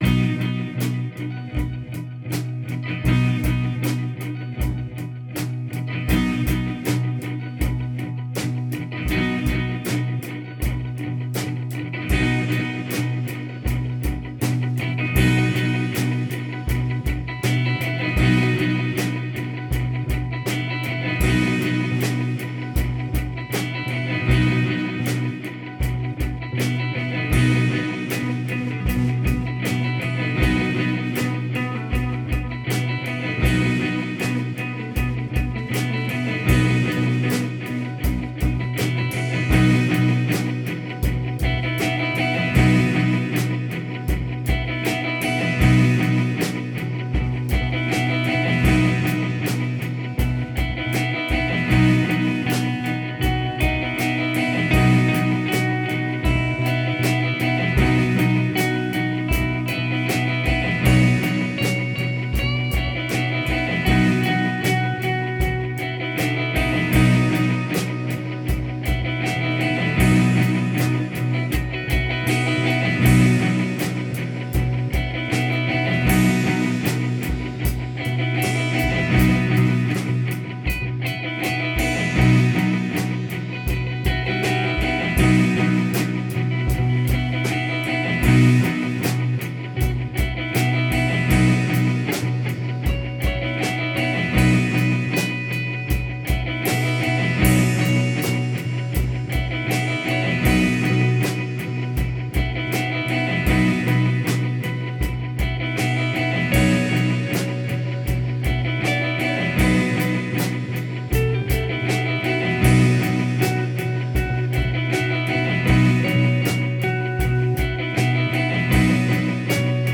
A three piece
We finally locked in pretty well.